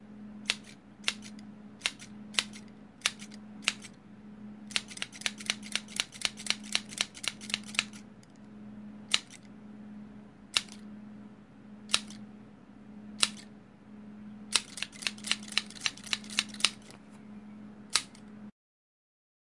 剪刀
描述：一把剪刀被打开和关闭（切割）。 采用Samson R21S，Stereo，.WAV，48KHZ 24bit录制。
Tag: 剪刀 锋利剪刀 切割 剪切割 金属 OWI